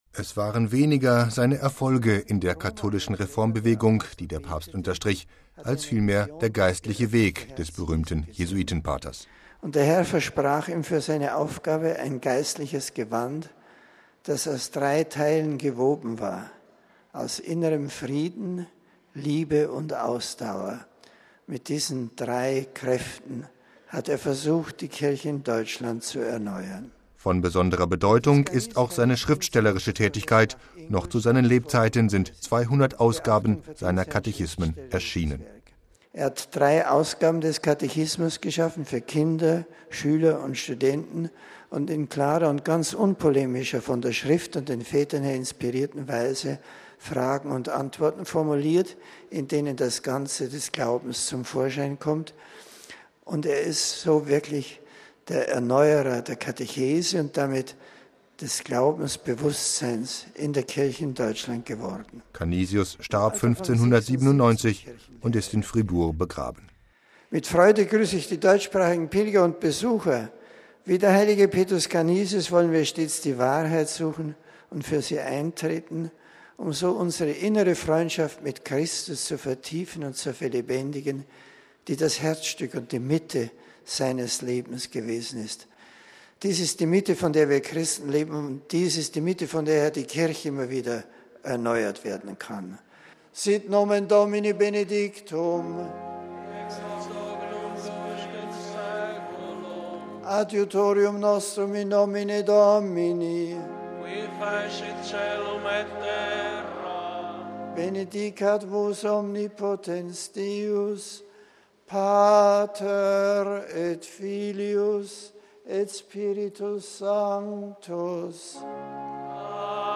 MP3 Erfolgreicher Schriftsteller, Konzilstheologe, Kirchenlehrer: Papst Benedikt XVI. hat in seiner Katechese zur Generalaudienz an diesem Mittwoch über den heiligen Petrus Canisius gesprochen.